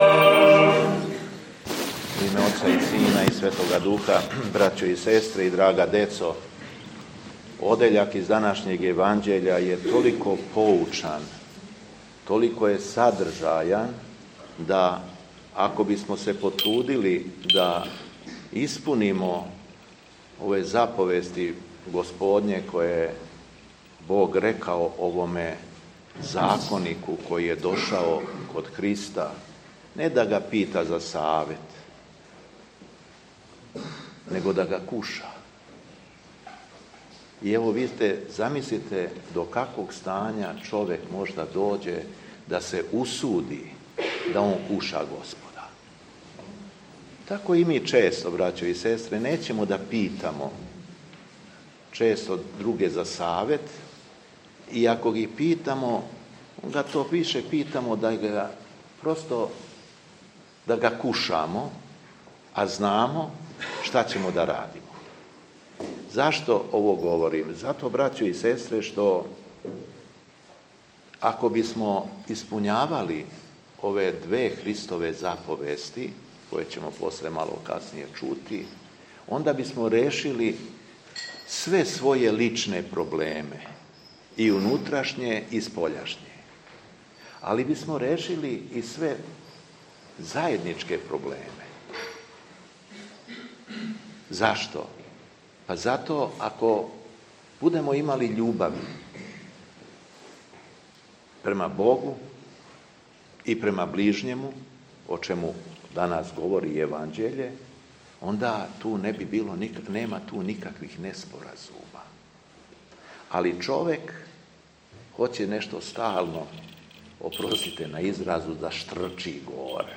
ЛИТУРГИЈСКО САБРАЊЕ У ХРАМУ ПРЕНОСА МОШТИЈУ СВЕТОГ НИКОЛЕ У КРАГУЈЕВАЧКОМ НАСЕЉУ ЕРДЕЧ - Епархија Шумадијска
Беседа Његовог Високопреосвештенства Митрополита шумадијског г. Јована
Беседећи верном народу Митрополит Јован је рекао: